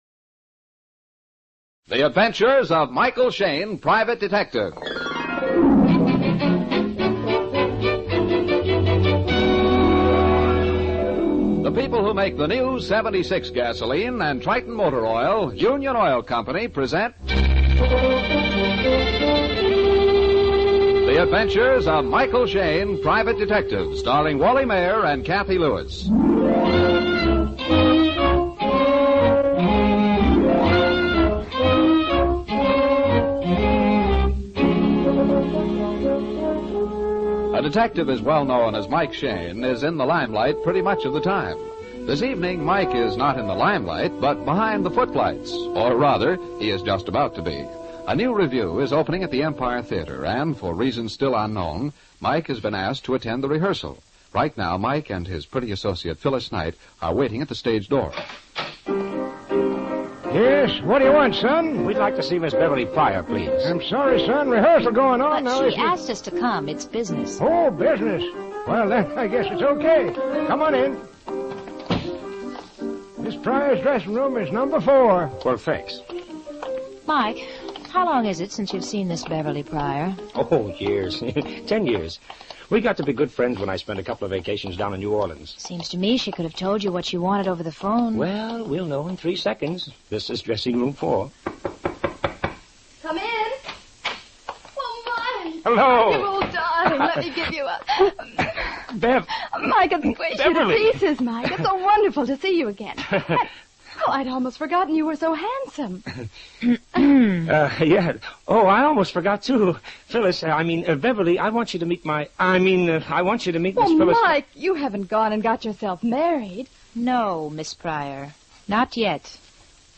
Michael Shayne 450827 Behind The Footlights, Old Time Radio